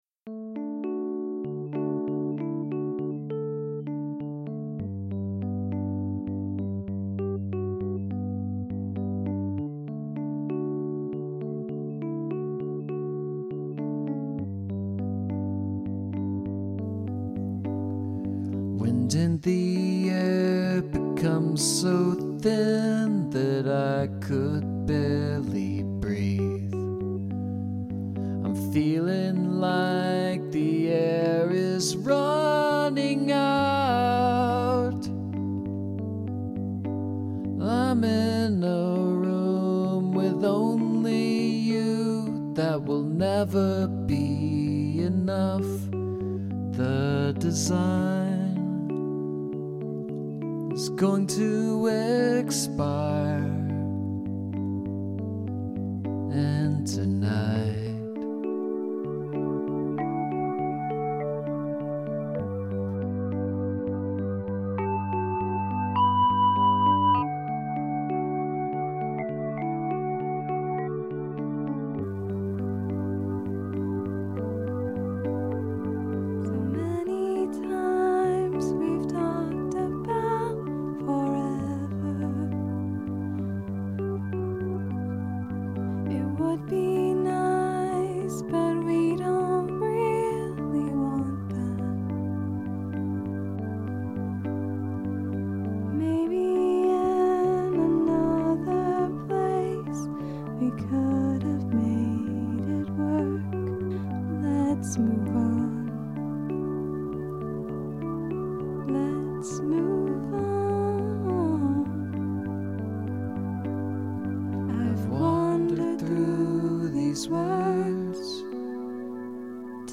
Your voices go together well.
nice harmony, love the drums, great song.
Delicate voices.
Even the vocals were recorded on a standard Shure SM57 borrowed from a set of my roommate's drum mics.
implosion-in-d.mp3